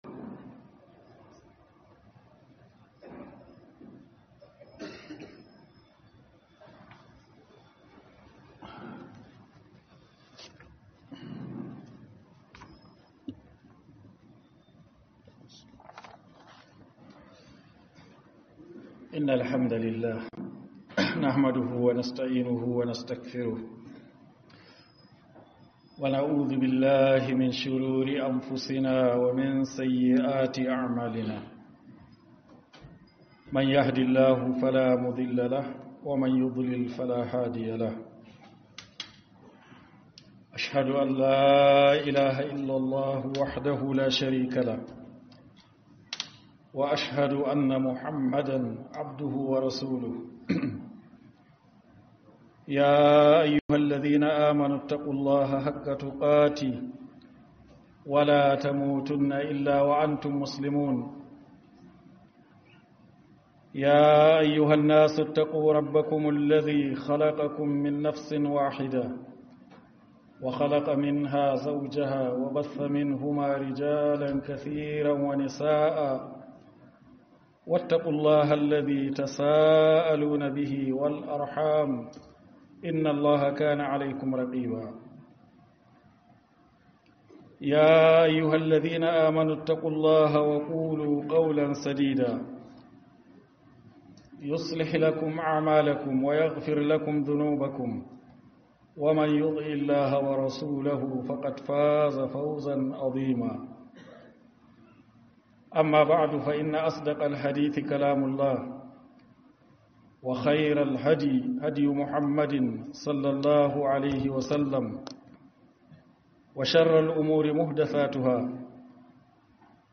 Kiran Sallar Asuba - Huduba